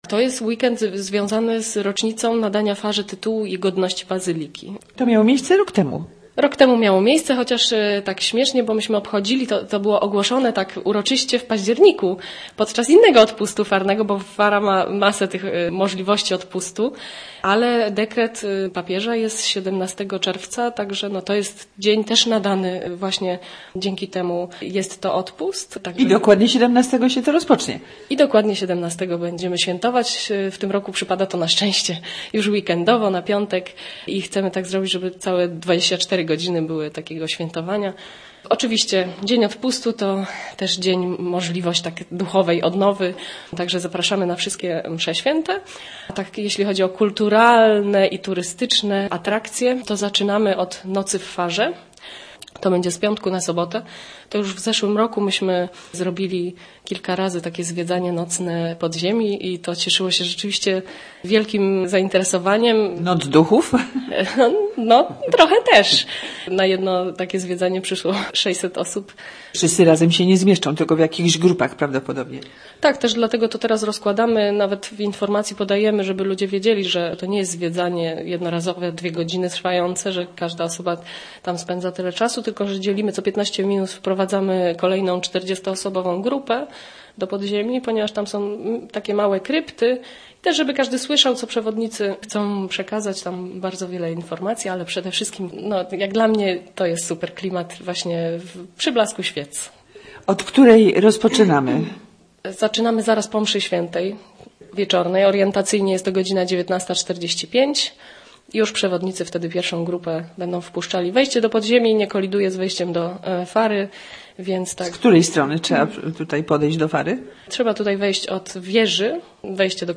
Nocą Fara zostanie otwarta dla amatorów turystycznych wycieczek w blasku świec oraz entuzjastów nocnych koncertów organowych. O szczegółach w rozmowie